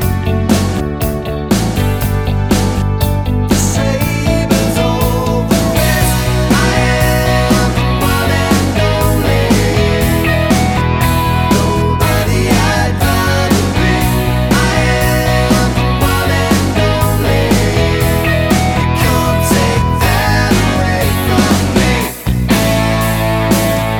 Two Semitones Down Pop (1990s) 3:36 Buy £1.50